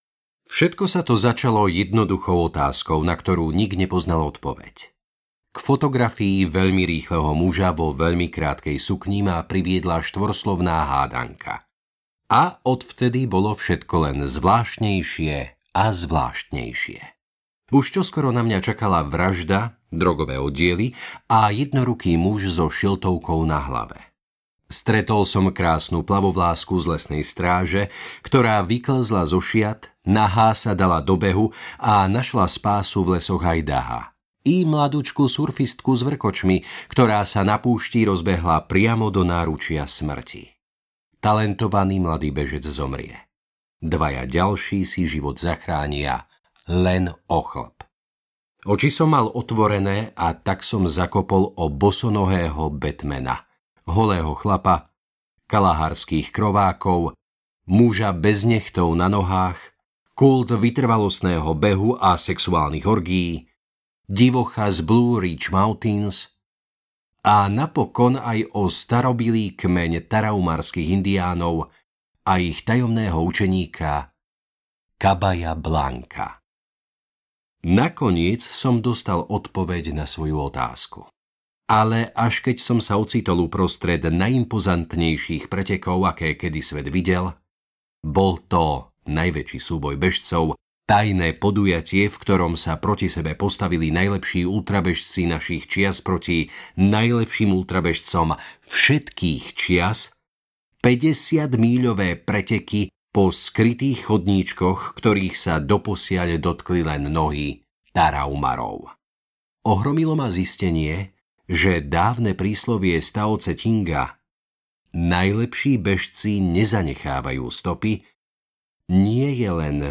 Stvorení pre beh (Born To Run) audiokniha
Ukázka z knihy